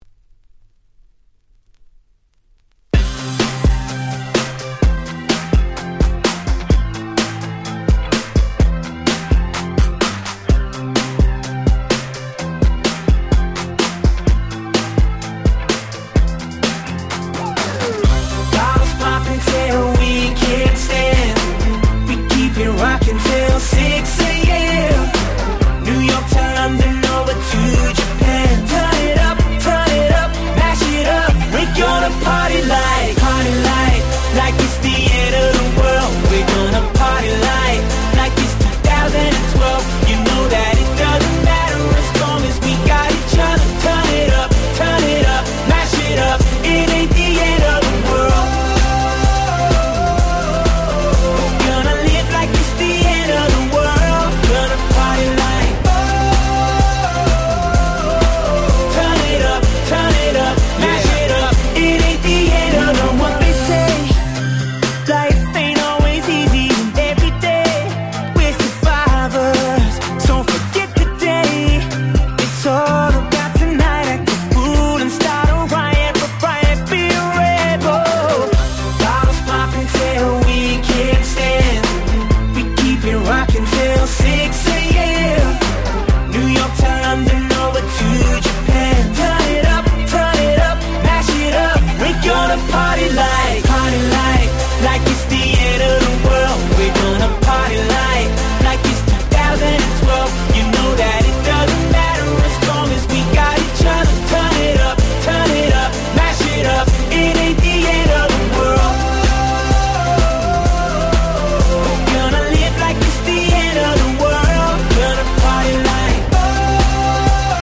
10' Super Hit R&B !!
超キャッチー！！